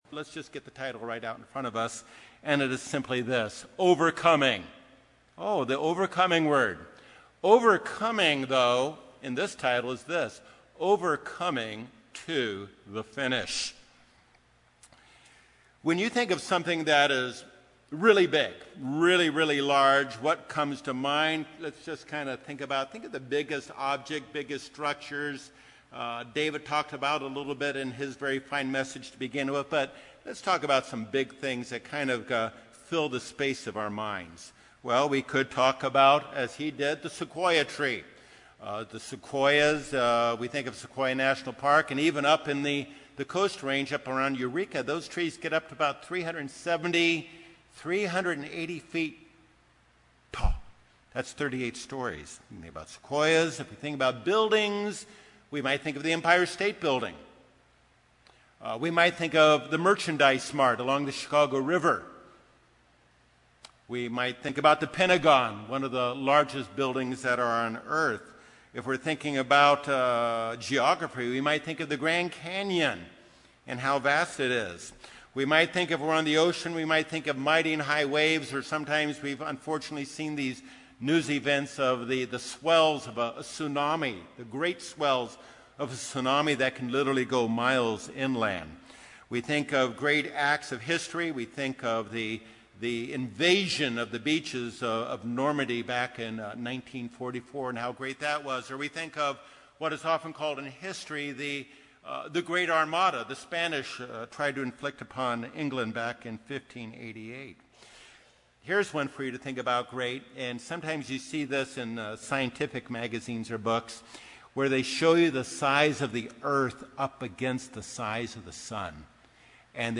This sermon addresses 3 specific keys to enable you with God's help to master whatever challenge lies before you now and in the future.